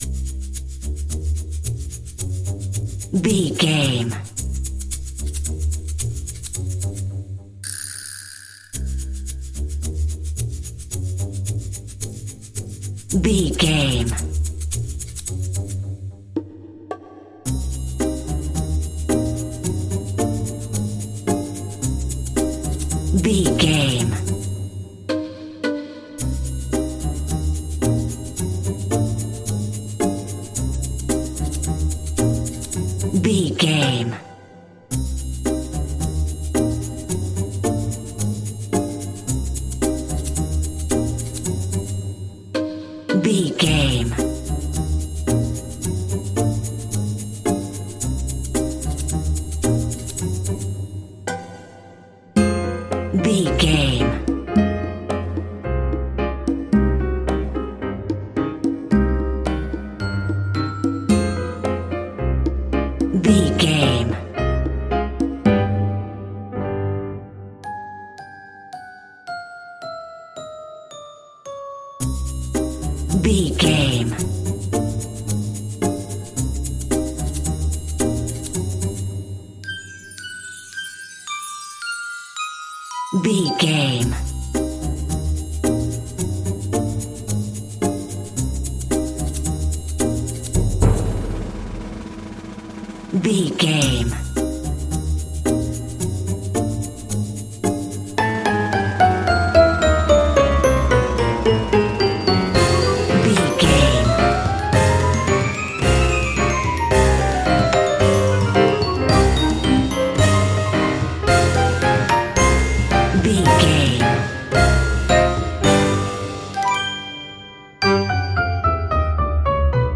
Aeolian/Minor
percussion
strings
silly
circus
goofy
comical
cheerful
perky
Light hearted
quirky